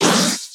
stunned_2.ogg